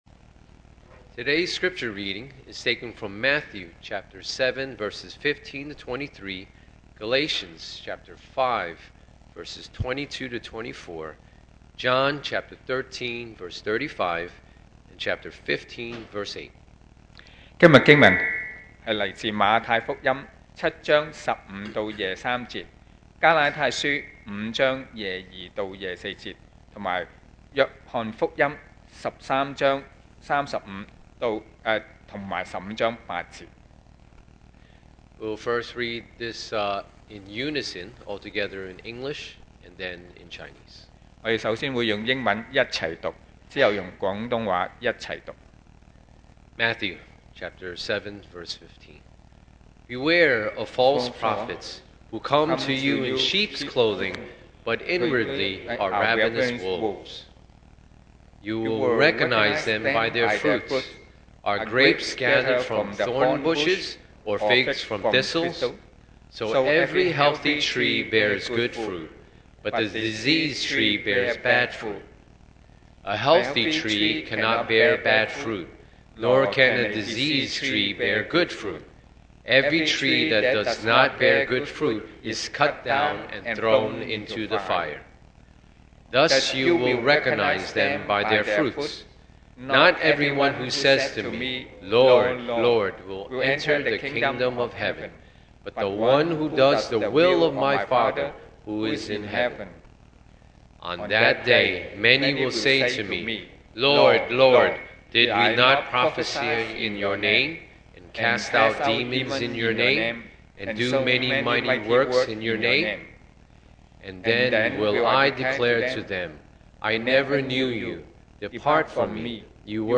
2024 sermon audios
Service Type: Sunday Morning